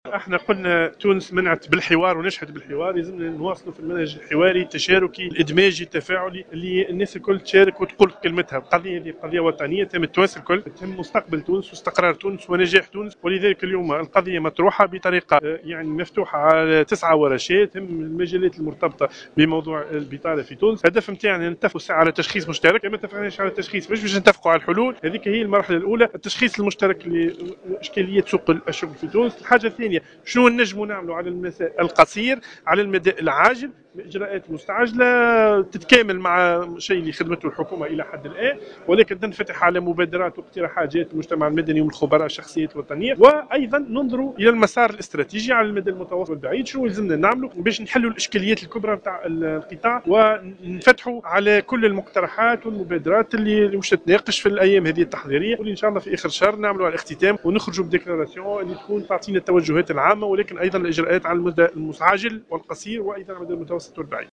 وأفاد في تصريحات صحفية على هامش افتتاح ورشات عمل الحوار الوطني للتشغيل اليوم بالعاصمة تونس، أن هذه التظاهرة ستتطرق إلى مجالات مرتبطة بالبطالة من خلال 9 ورشات متخصصة.